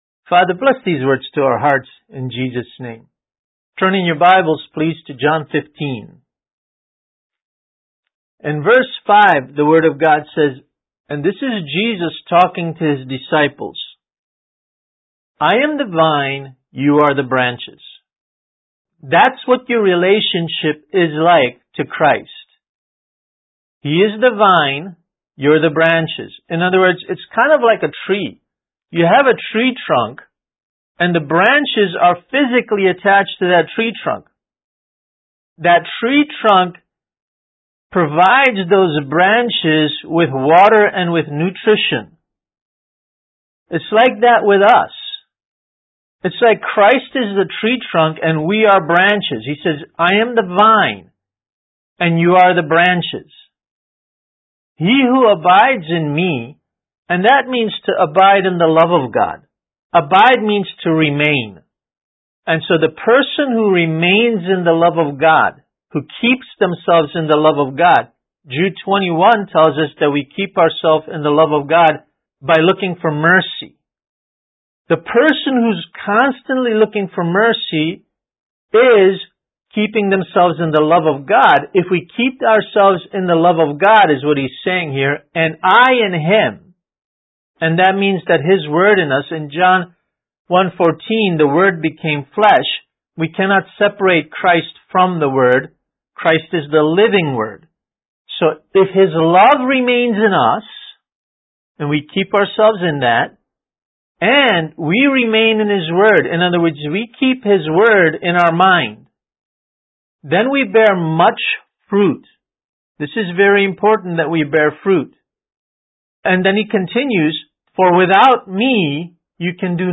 Kids Message: Our Source of Confidence